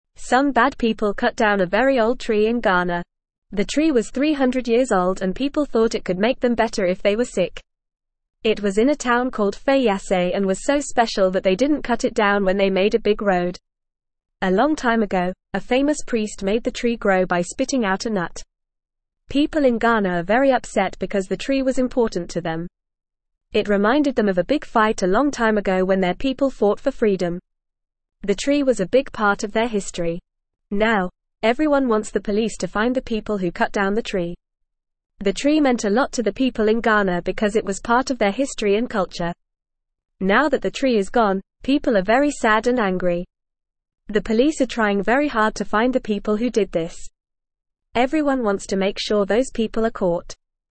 Fast
English-Newsroom-Lower-Intermediate-FAST-Reading-Old-Tree-in-Ghana-Cut-Down-by-Bad-People.mp3